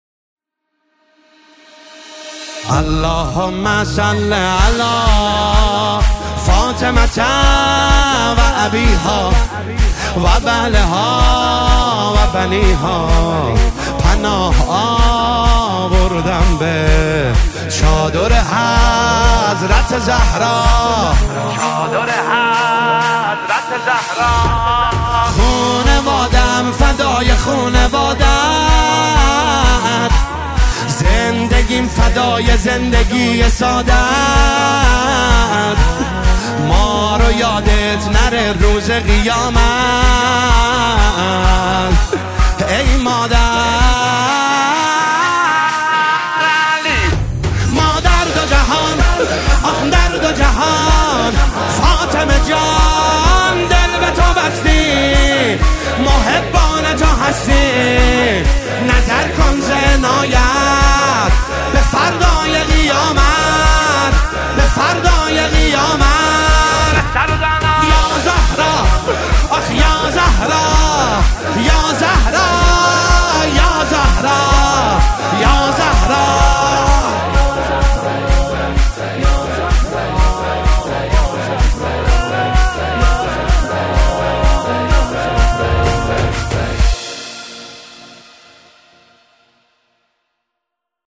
فاطمیه 1404